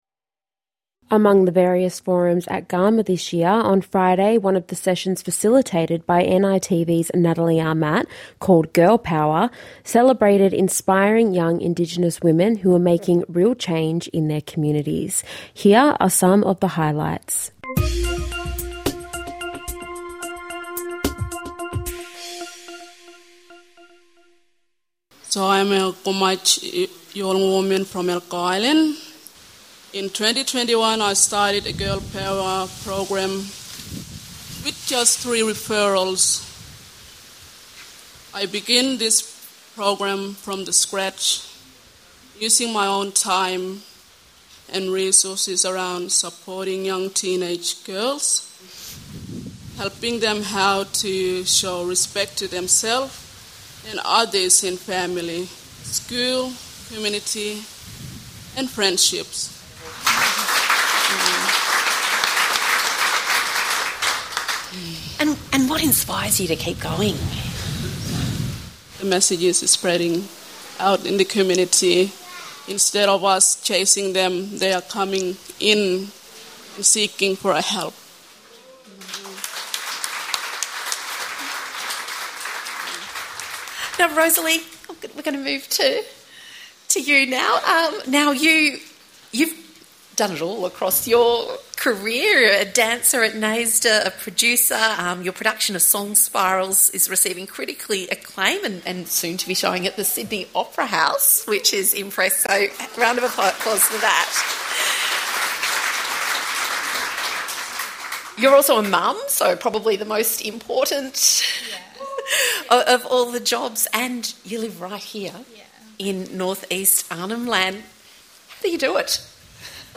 NITV Radio brings you highlights from this years 2025 Garma Festival.
Girl Power was one of the forums on Friday at the 25th Garma Festival.